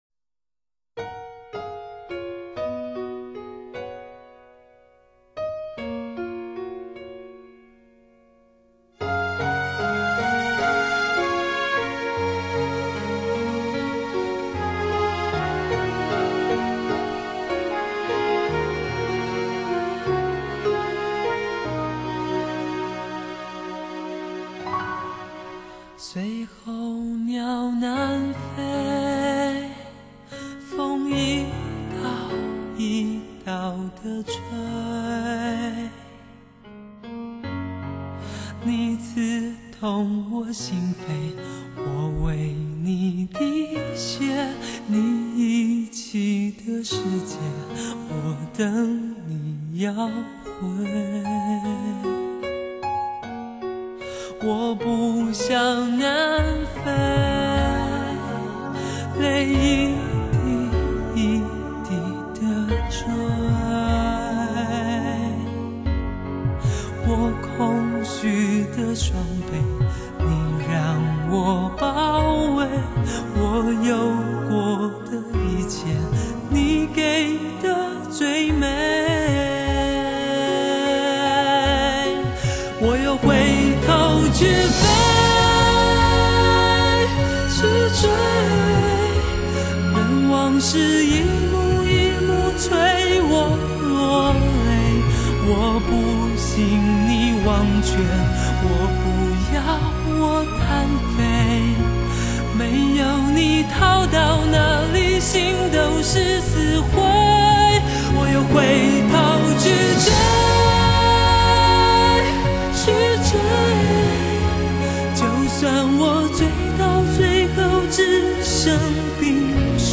创作曲风越发成熟，结构严谨中不乏浪漫悠雅